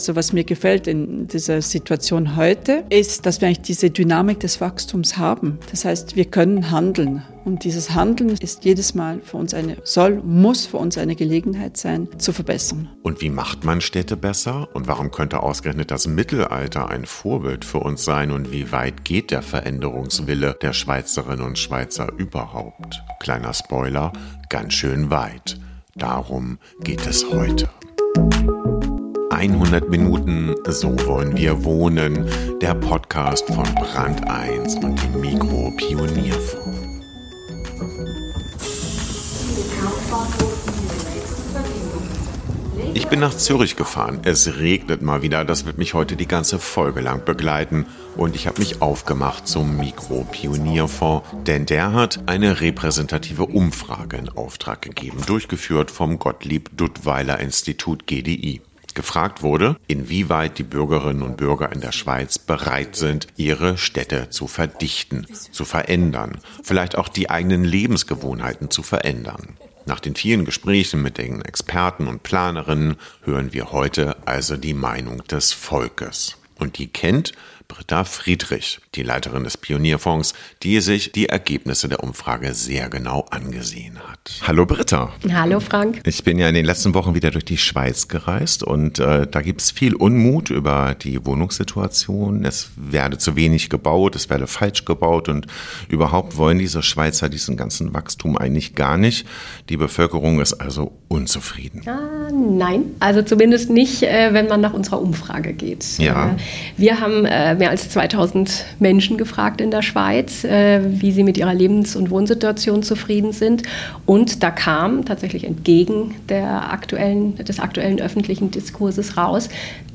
Ein Podcast mit viel Regen, Schnee und Löcher-bohrenden Nachbarn.